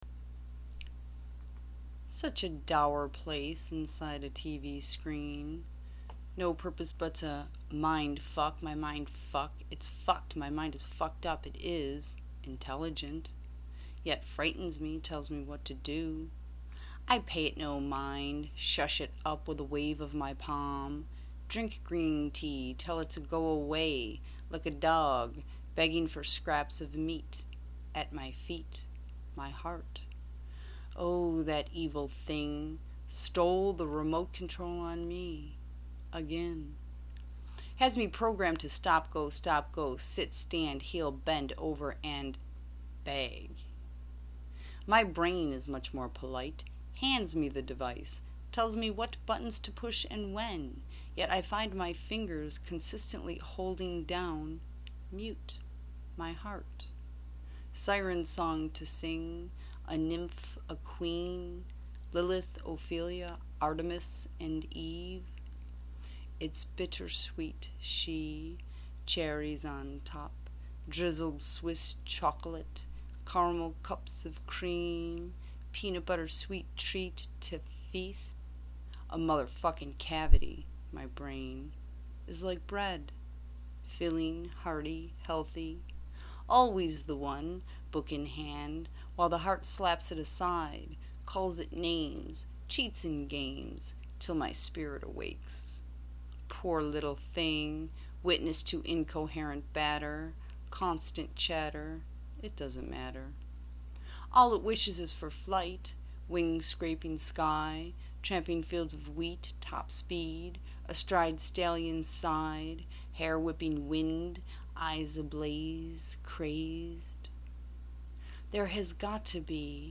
Spoken Word by me: